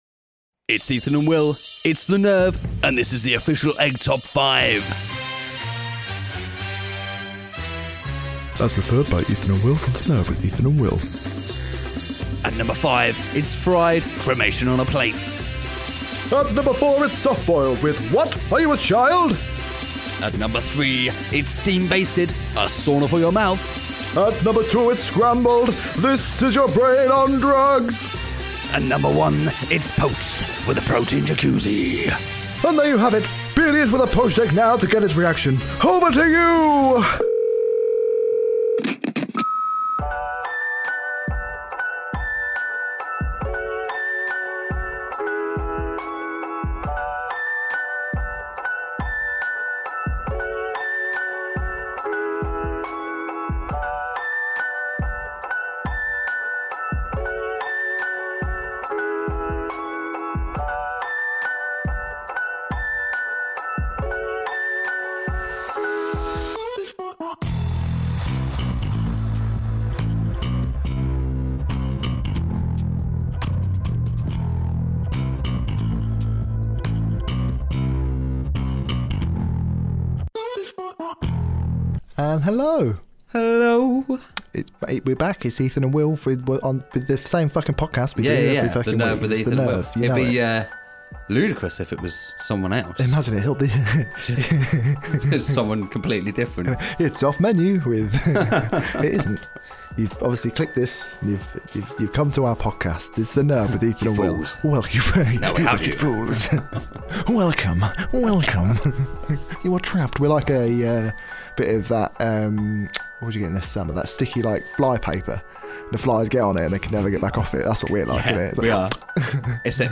We have another nonsense filled chat for you and this week: - We have the official Egg top 5 - We talk about Paul Ritter - We have no emails! - We talk about the first BBQ of the year - And we tell each other some jokes at the end This podcast is full of swearing so if that offends you, this podcast isn't right for you!
(we own no music in this podcast apart from our theme song)